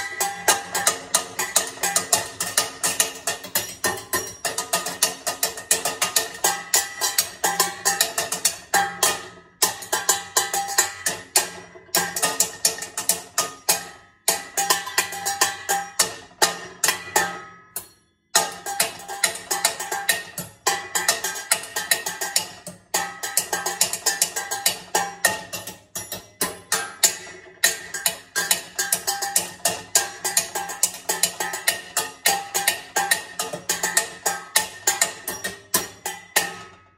the sounds of war
The_sound_of_war.mp3